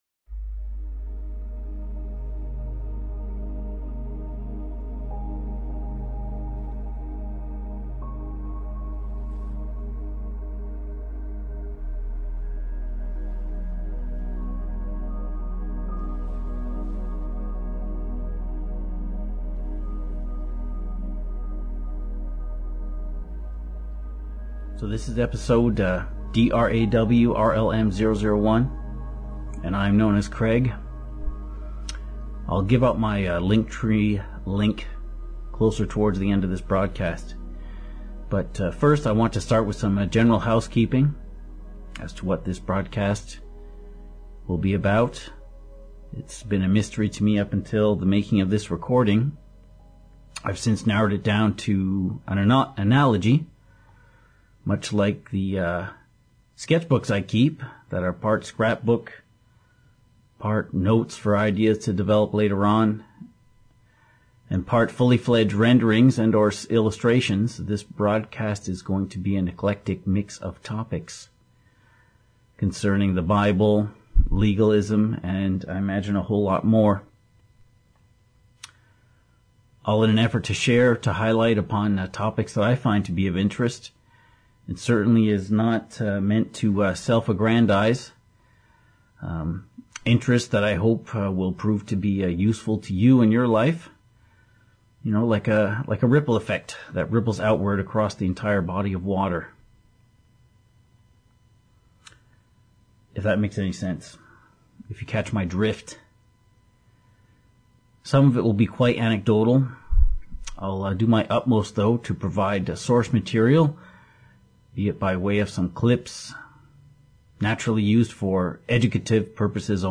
FM radio quality